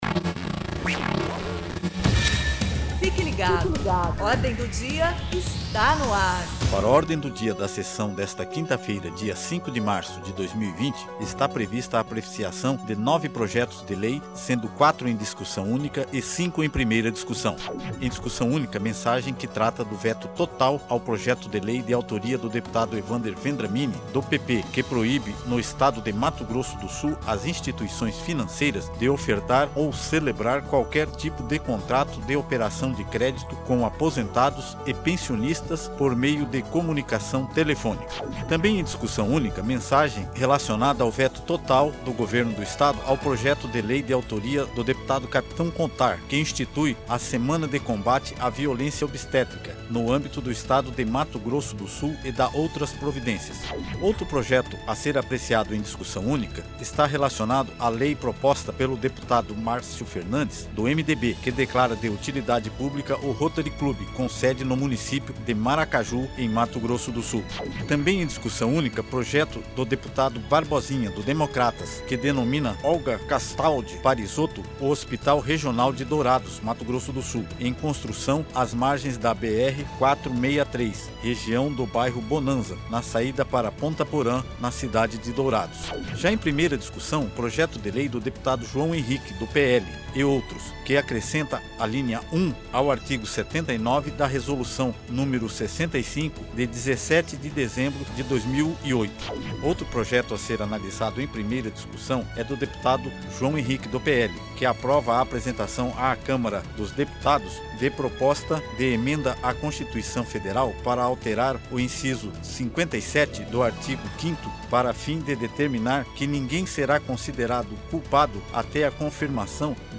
Download Locução e Produção